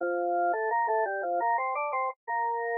贝斯和风琴
Tag: 器乐 蓝调 Otamatone